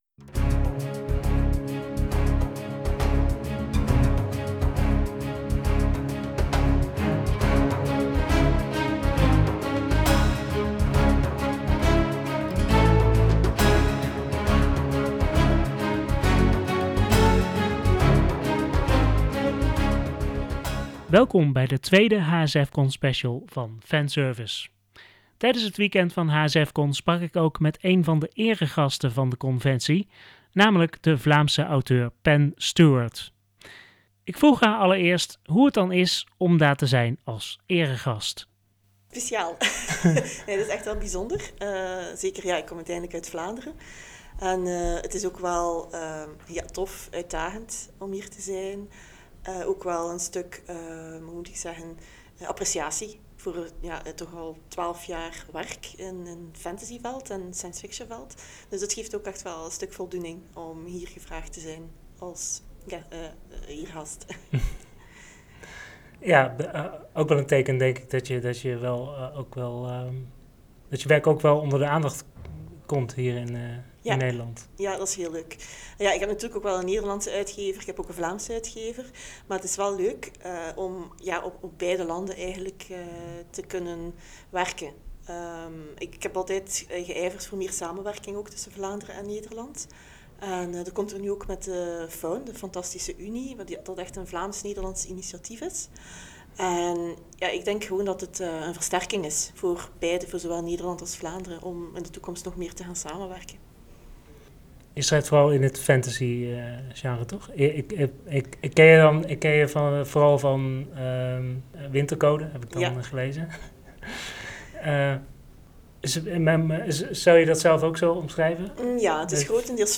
Tijdens HSF Con sprak ik ook met een van de eregasten van de conventie